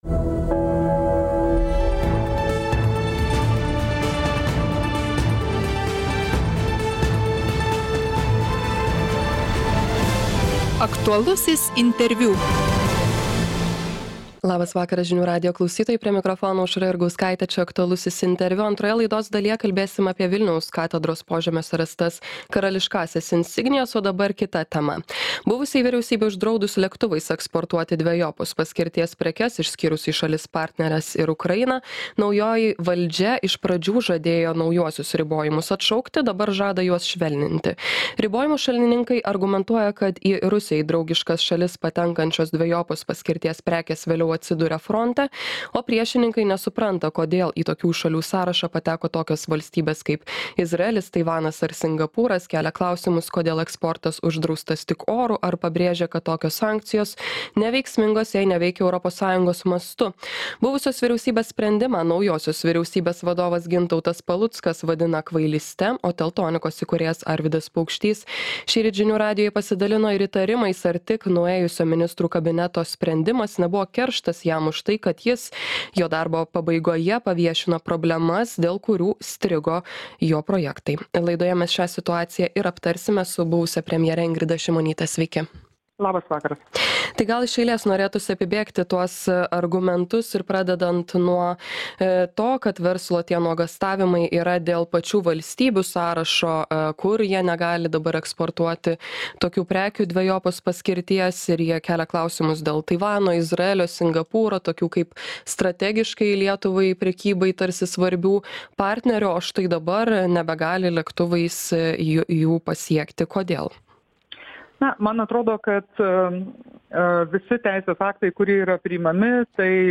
Laidoje dalyvauja iki šiol apie situaciją dar nepasisakiusi buvusi premjerė Ingrida Šimonytė.